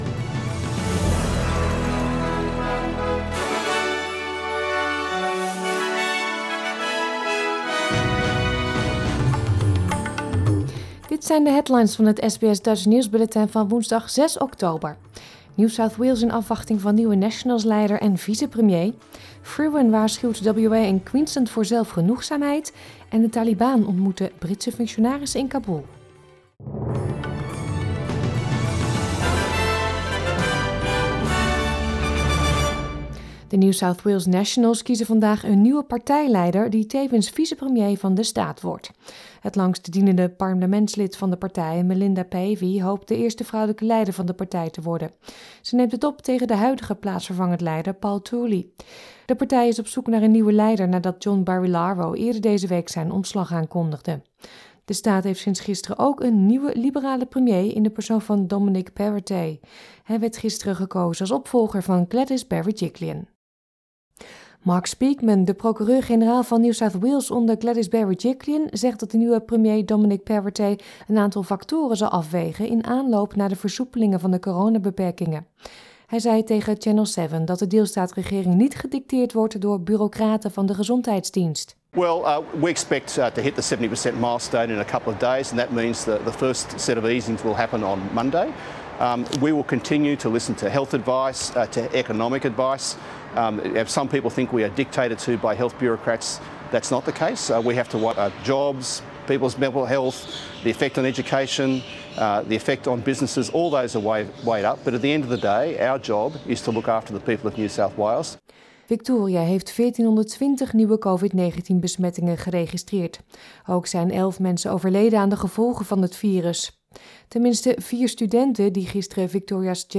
Nederlands/Australisch SBS Dutch nieuwsbulletin van woensdag 6 oktober 2021